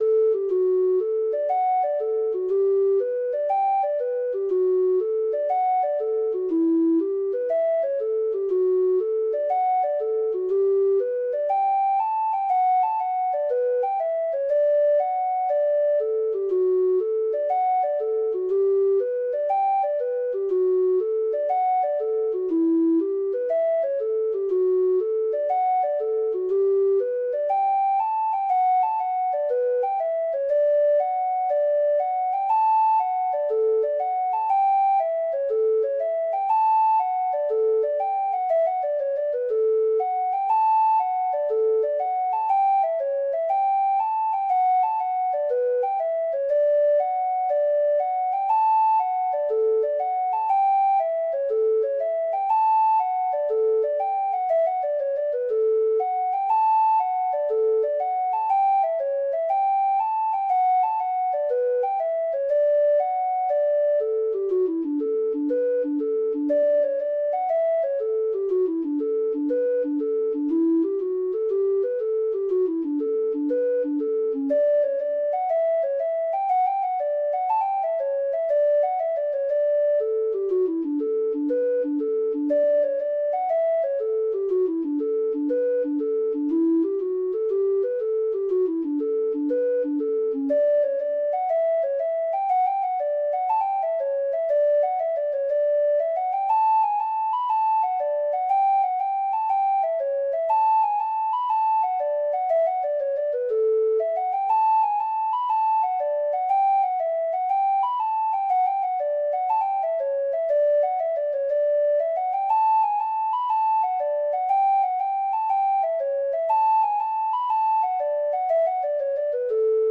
Free Sheet music for Treble Clef Instrument
Traditional Music of unknown author.
Hornpipes
Irish